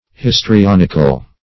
histrionical - definition of histrionical - synonyms, pronunciation, spelling from Free Dictionary
Histrionic \His`tri*on"ic\, Histrionical \His`tri*on"ic*al\, a.